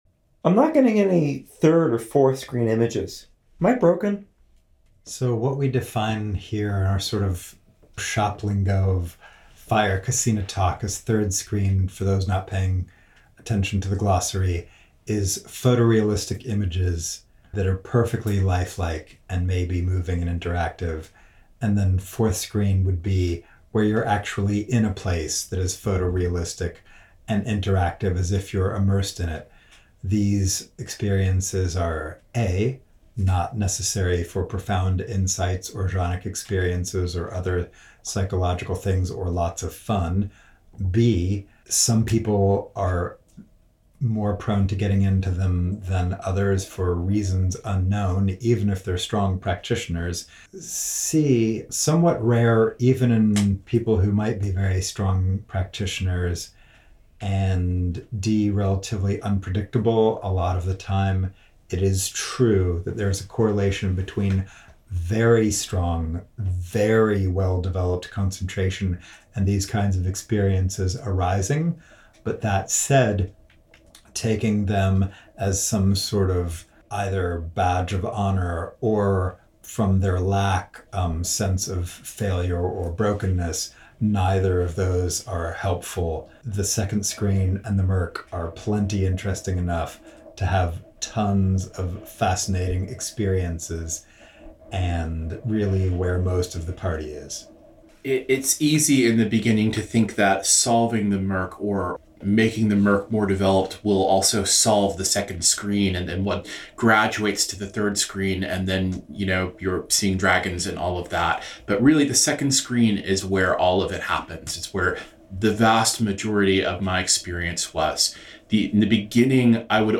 We assembled a list of about 65 most commonly asked questions during the retreat, ordered them into some sort of coherent sequence, and then, at the end of the retreat, a group of us sat in a circle in the main meditation hall and answered them as a group, a process that took a few hours. The popping and crackling you hear in the background is the sound of the fire that kept us warm on that chilly Canadian night, which seems appropriate to discussing fire kasina.